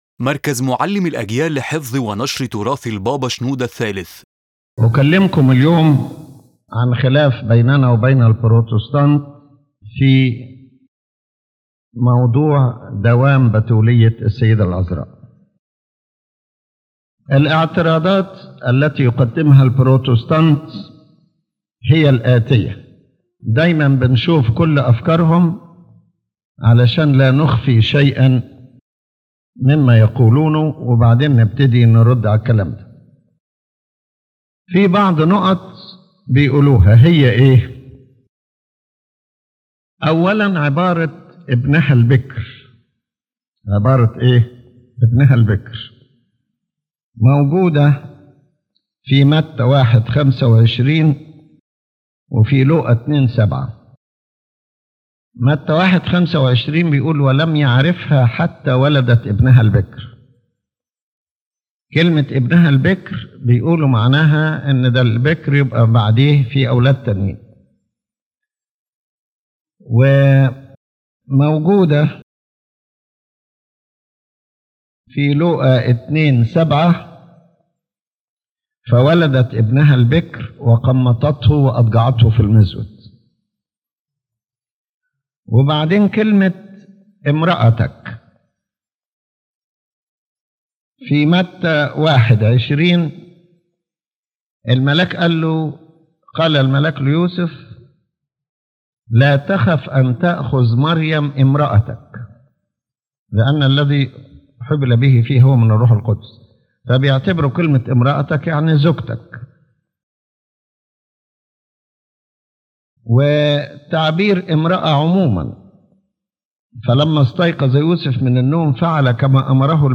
In this lecture, His Holiness Pope Shenouda III speaks about the perpetual virginity of the Virgin Mary, one of the major differences between the Orthodox and the Protestants. His Holiness presents the Protestant objections and replies with precise biblical and theological explanations.